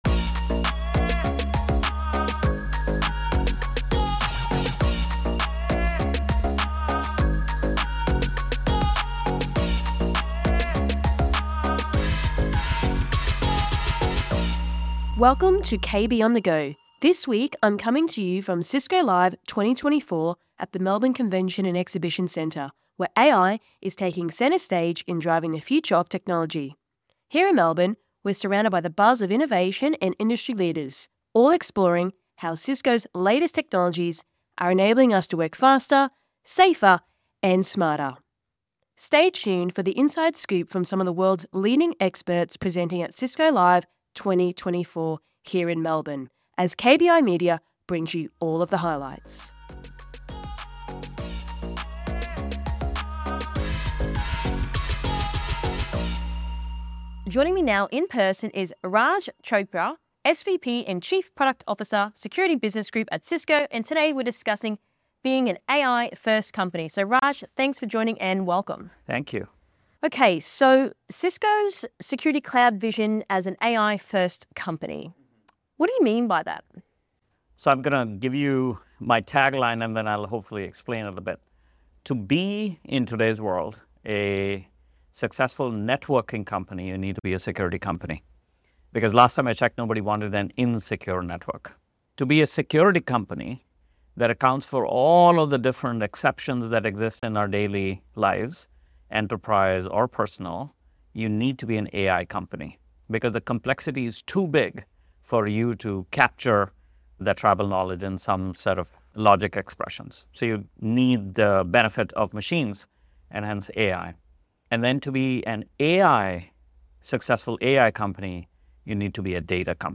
From Cisco Live 2024 Melbourne